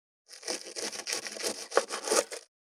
532厨房,台所,野菜切る,咀嚼音,ナイフ,調理音,まな板の上,料理,
効果音厨房/台所/レストラン/kitchen食器食材